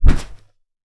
Foley Sports / Boxing / Punching Bag Rhythmic B.wav
Punching Bag Rhythmic B.wav